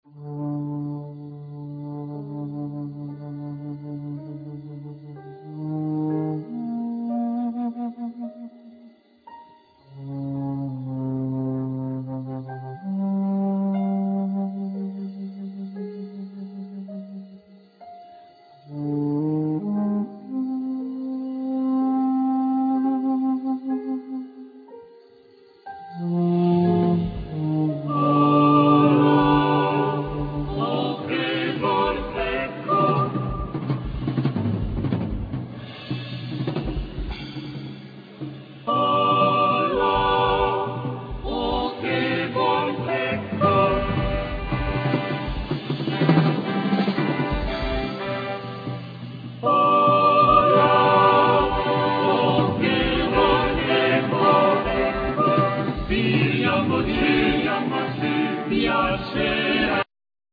Piano
Drums,Vibraphone
Soprano,Alto,Tenor,Bass
1st Violin,2nd Violin,Viola,Cello